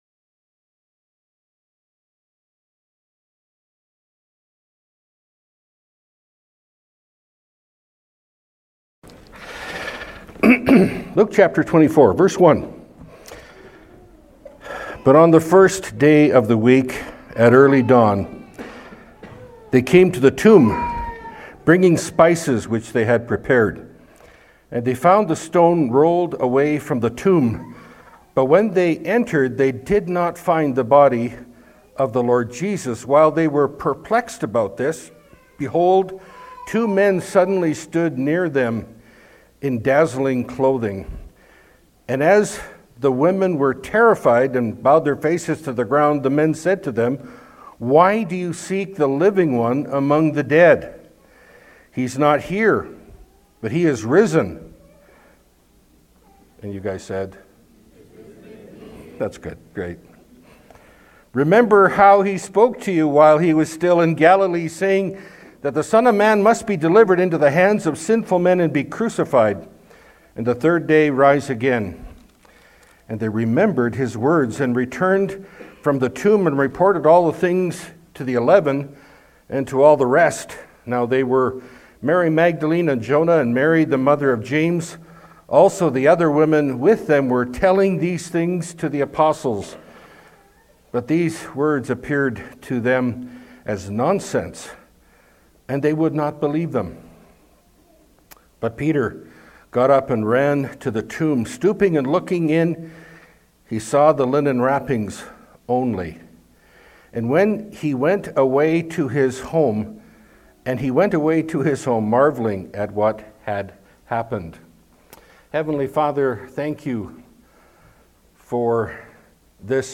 Luke Category: Pulpit Sermons Key Passage